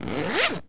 foley_zip_zipper_long_03.wav